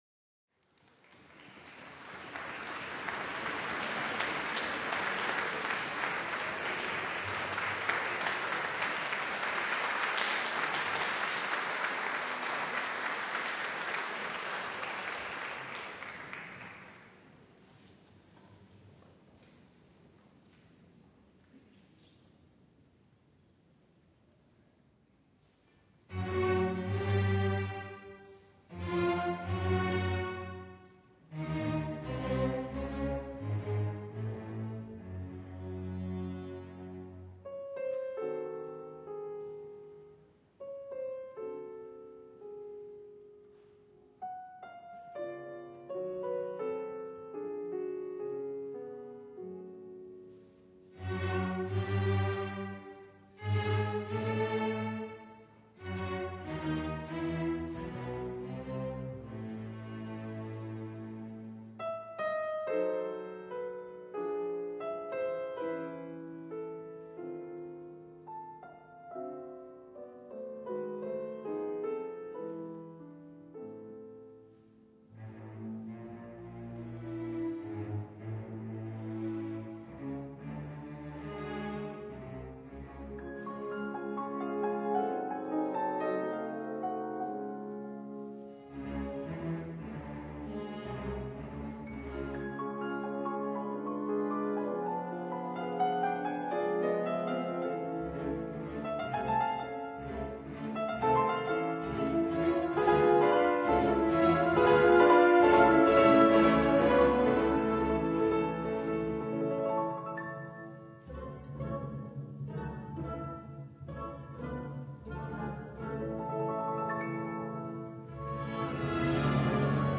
Audio LIVE